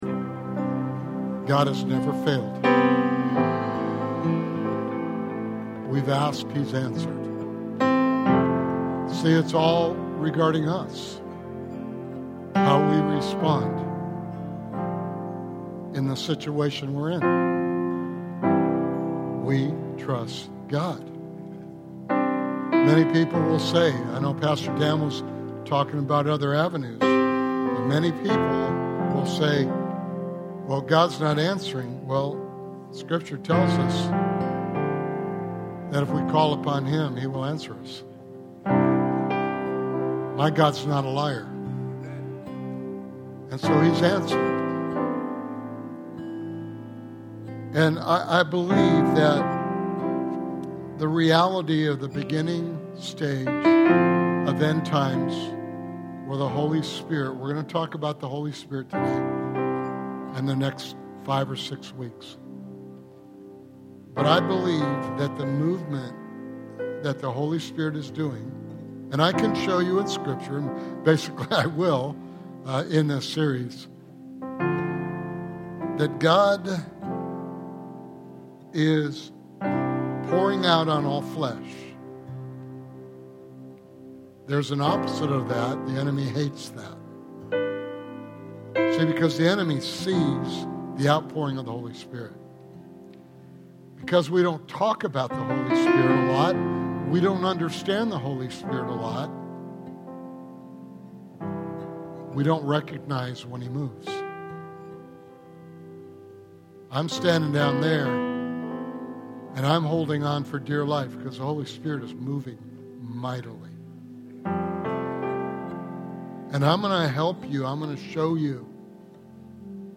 Sermon Series: The God I Never Knew